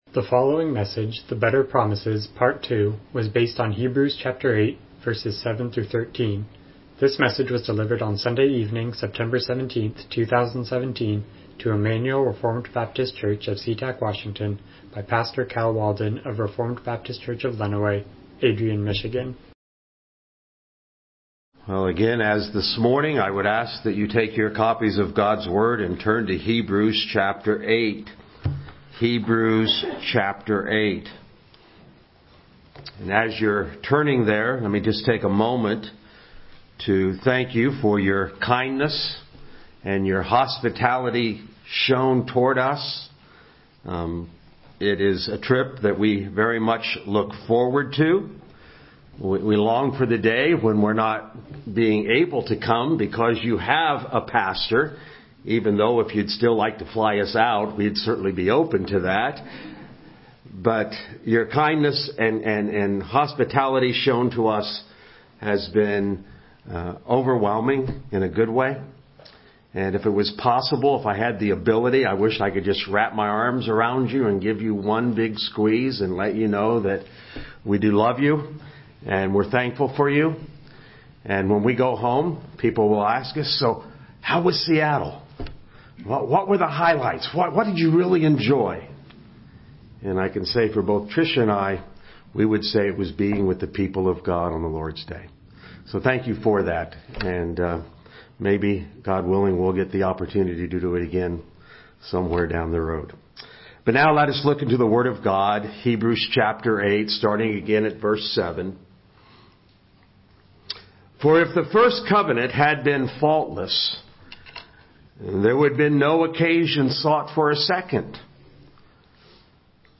Miscellaneous Passage: Hebrews 8:7-13 Service Type: Evening Worship « The Better Promises